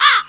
crow22a.wav